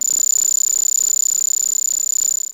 Lock.wav